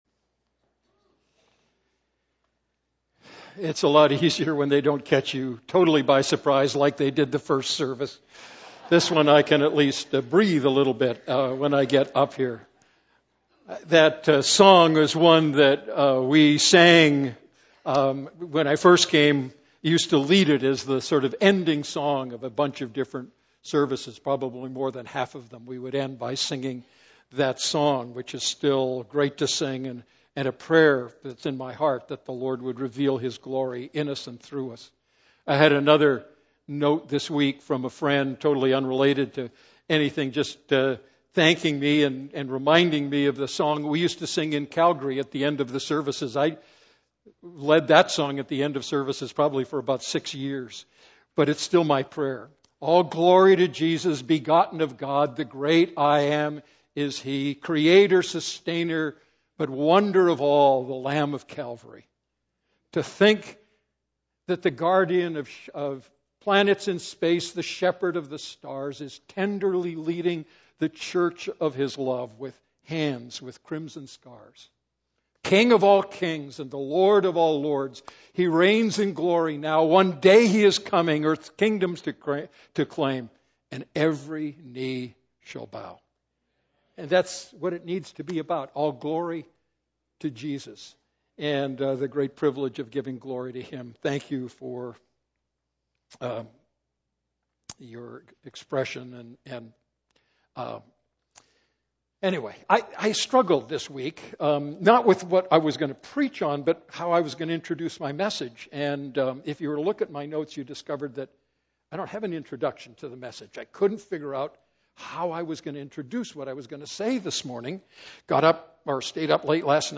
A message from the series "Going for the Gold."